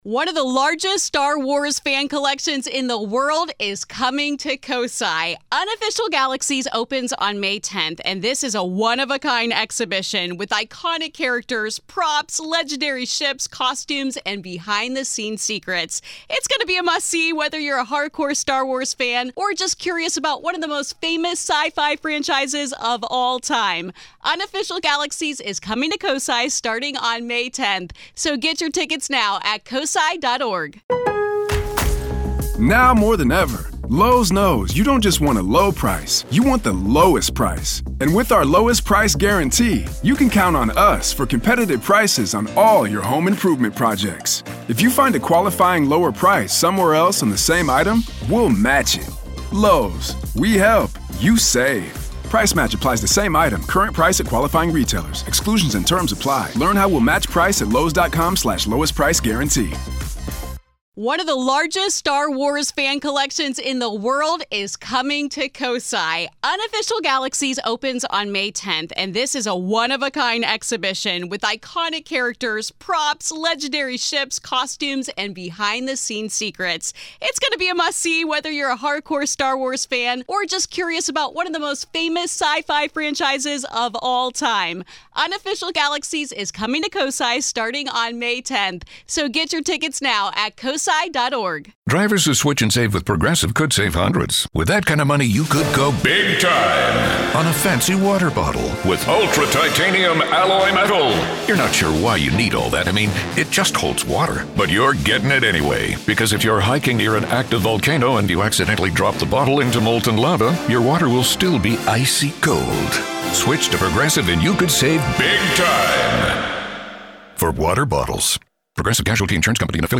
At three years old, our caller and his baby brother shared a crumbling Ohio duplex where front doors locked themselves, a steam-spewing toy robot cackled after midnight, and moods spiraled into sudden rage.